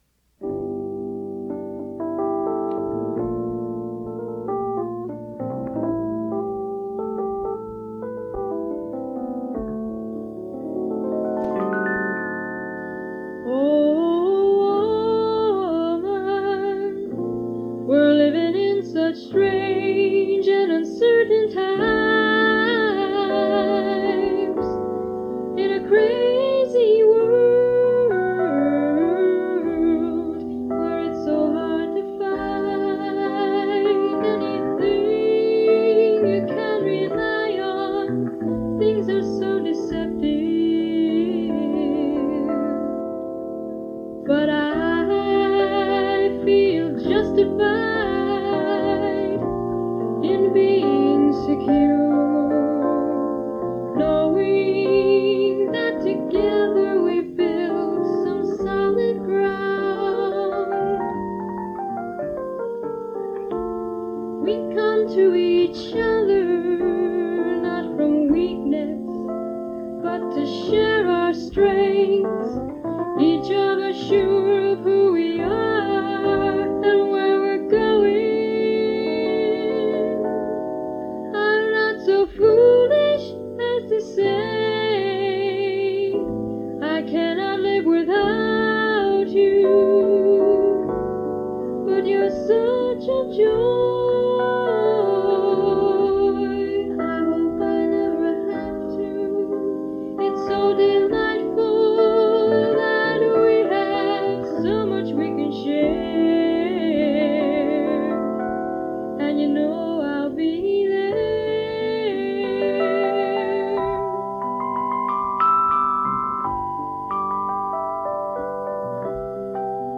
singing and playing bass
keyboard
and often singing harmony or sharing the lead vocal.
Unfortunately, some of the recordings are not the greatest in terms of quality